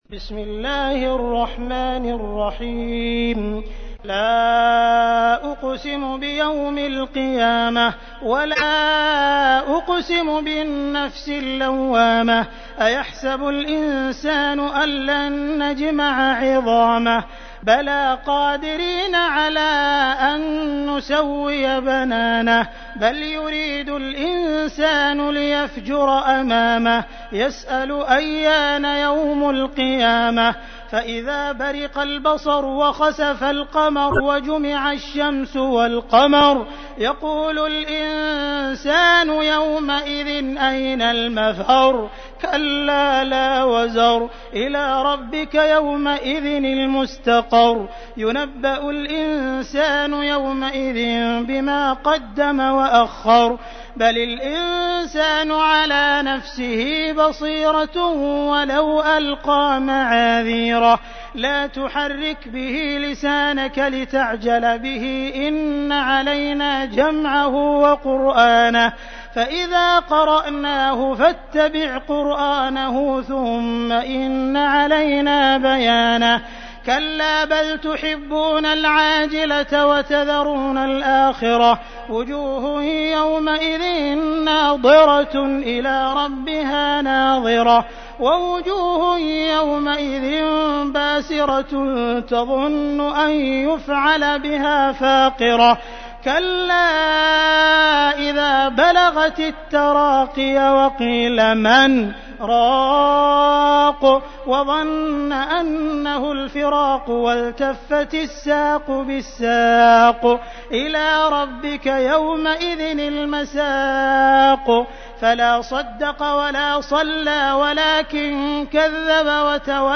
تحميل : 75. سورة القيامة / القارئ عبد الرحمن السديس / القرآن الكريم / موقع يا حسين